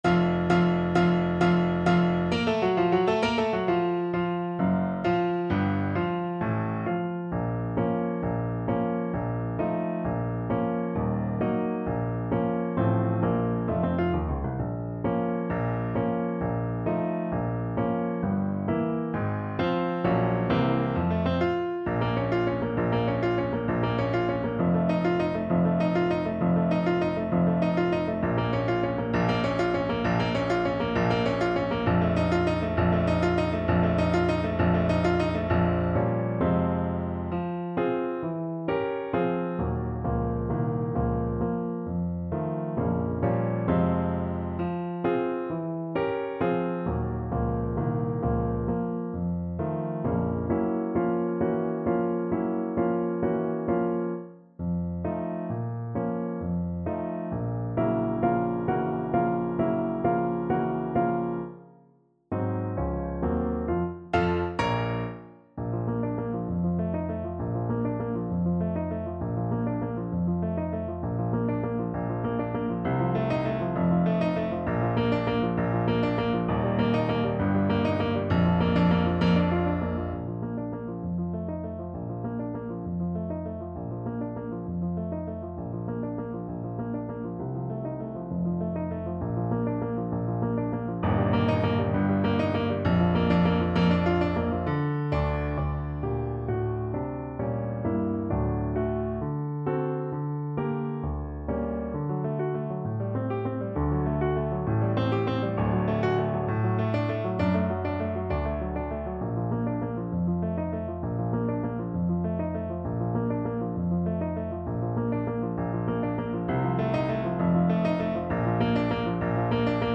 Play (or use space bar on your keyboard) Pause Music Playalong - Piano Accompaniment Playalong Band Accompaniment not yet available reset tempo print settings full screen
Bb minor (Sounding Pitch) C minor (Trumpet in Bb) (View more Bb minor Music for Trumpet )
6/8 (View more 6/8 Music)
~. = 132 Allegro con spirito (View more music marked Allegro)
Classical (View more Classical Trumpet Music)